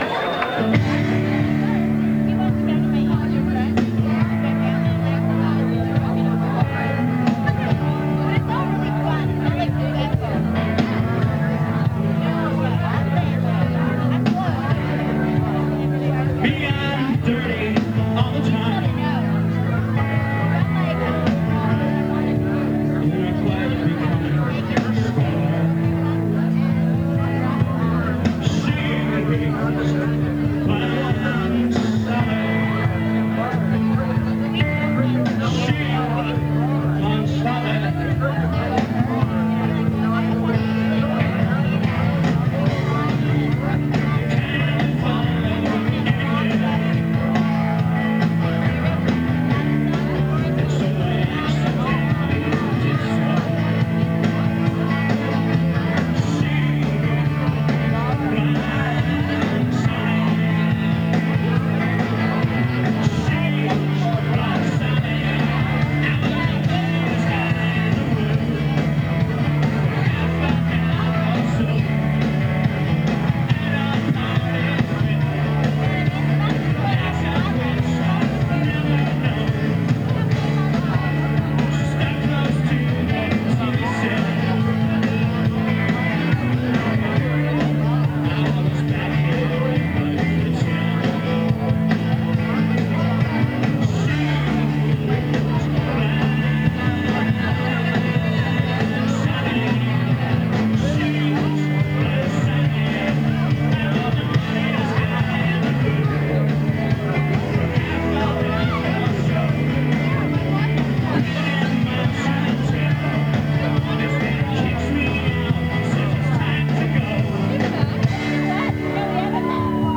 Live In 1992
Source: Audience